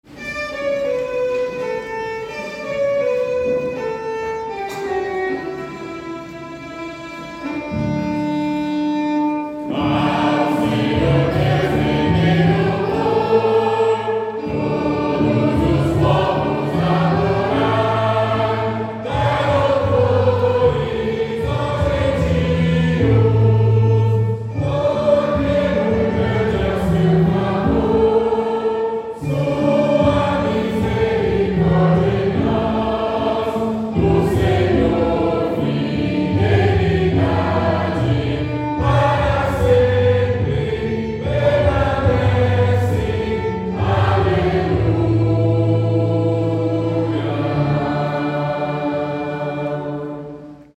Salmos metrificados para o canto em língua portuguesa
Downloads Áudio Áudio cantado (MP3) Áudio instrumental (MP3) Áudio instrumental (MIDI) Partitura Partitura 4 vozes (PDF) Cifra Cifra (PDF) Cifra editável (Chord Pro) Mais opções Página de downloads
salmo_117B_cantado.mp3